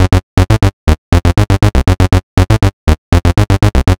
TSNRG2 Bassline 021.wav